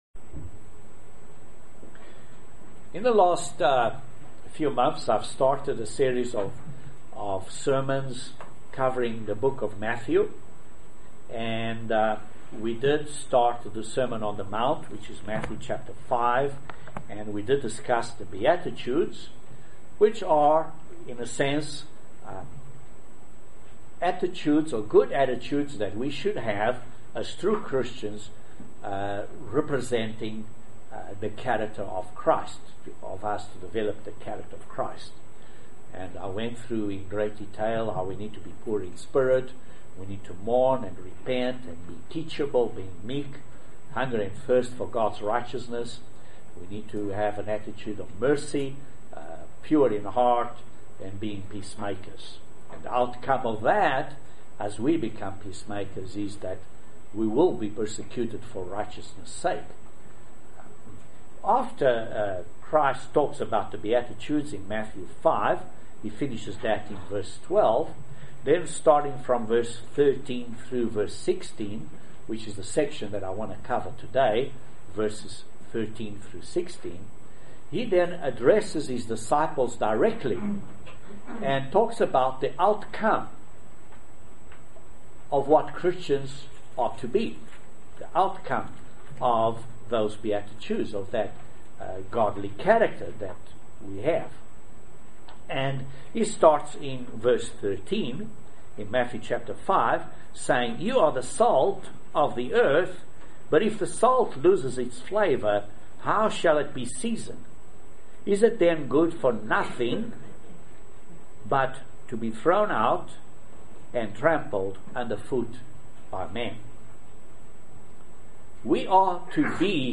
How do we get to be the Salt of the Earth? and how do we get to the point of being a Light to the World? These questions are answered in this Sermon.